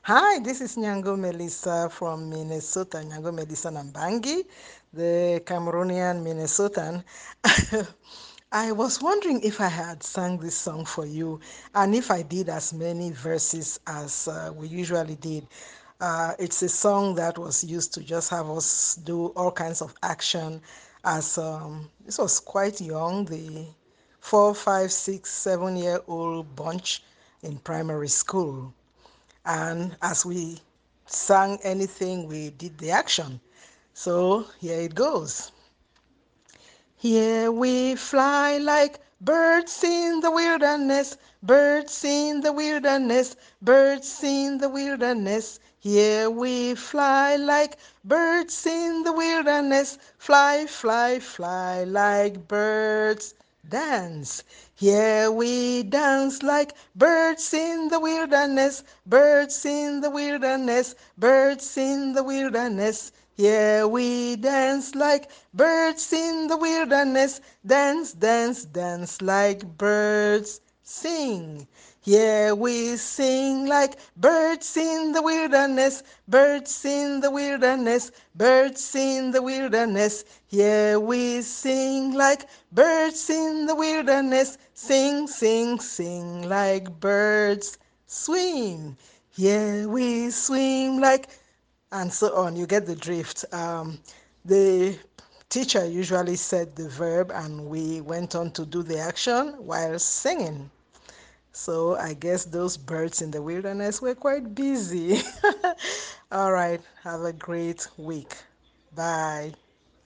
Here We Fly Like Birds in the Wilderness - Cameroonian Children's Songs - Cameroon - Mama Lisa's World: Children's Songs and Rhymes from Around the World